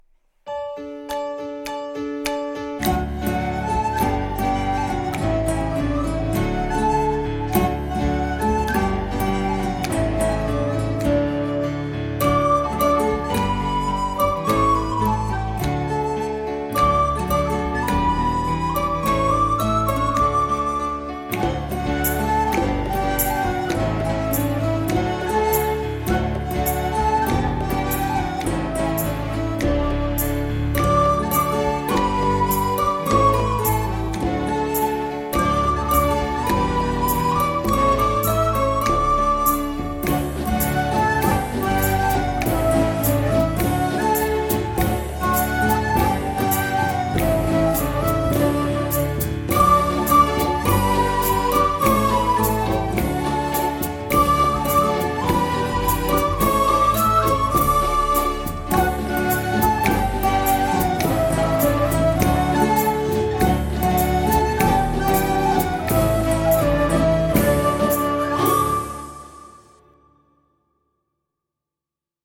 inkl. CD mit den Instrumentalmelodien der Lieder